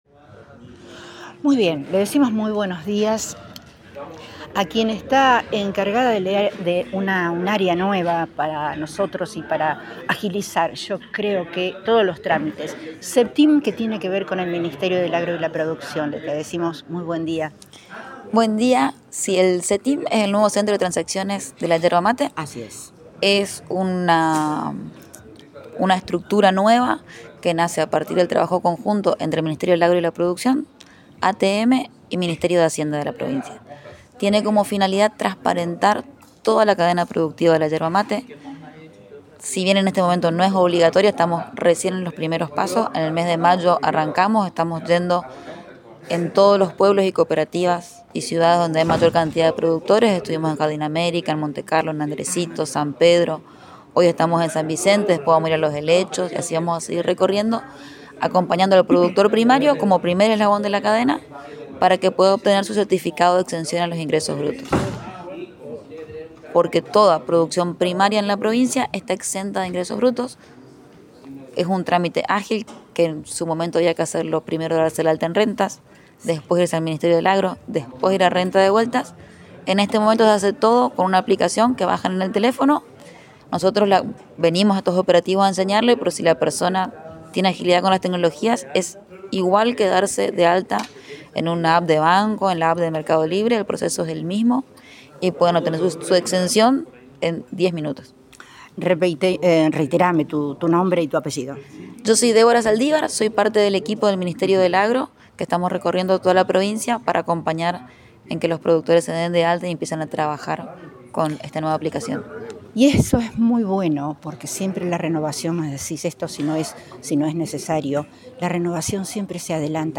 Audio nota